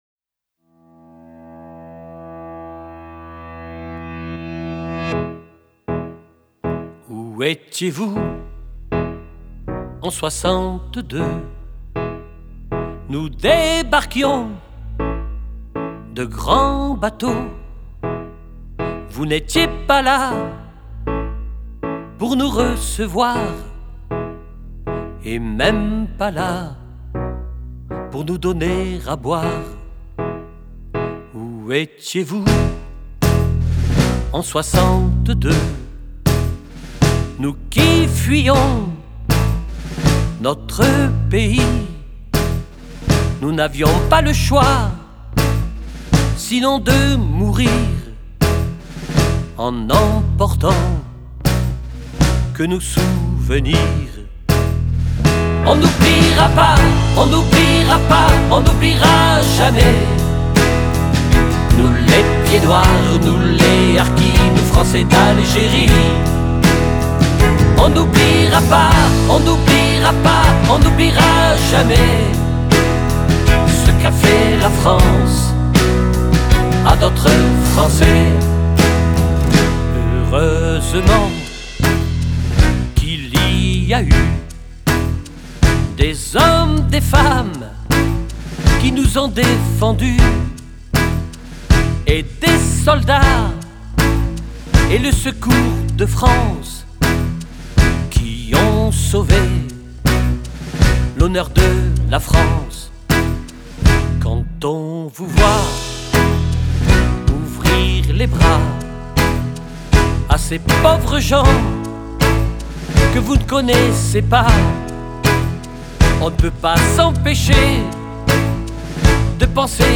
Chanson d'un chanteur Pied-Noir